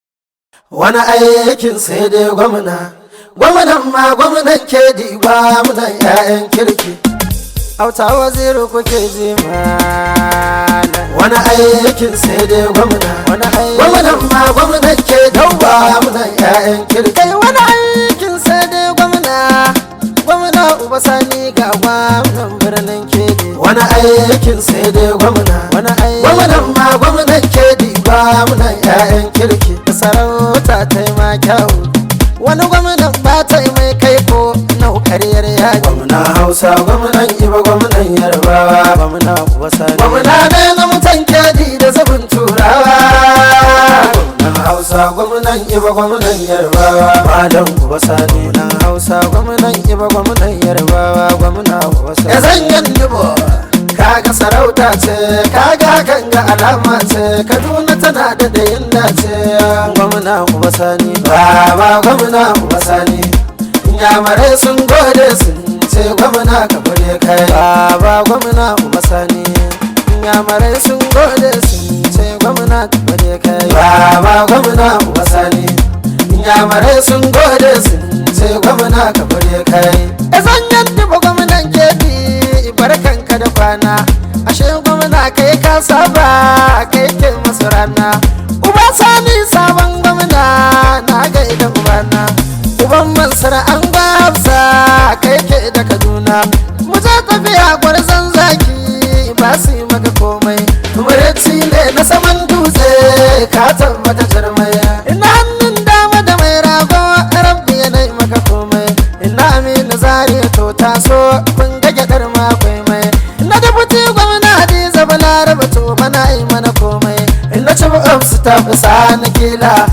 hausa music track
an Arewa rooted song